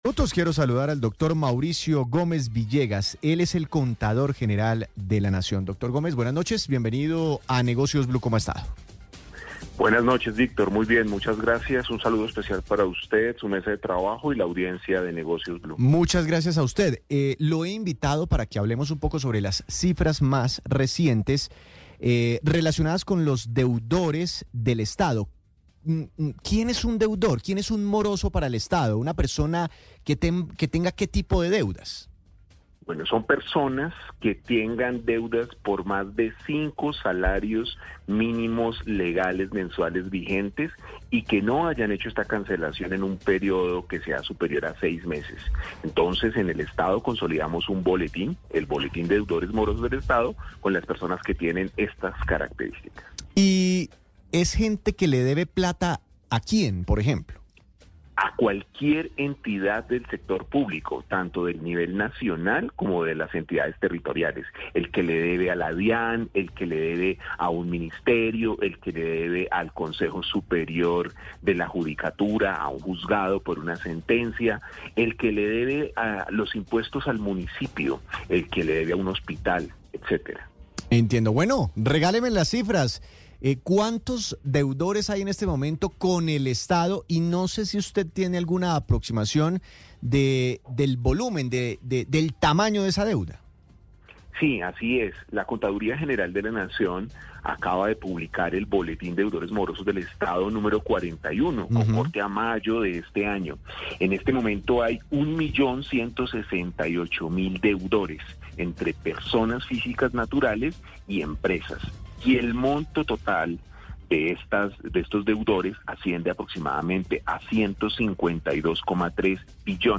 Entrevista-Blue-Radio
entrevista-blue-radio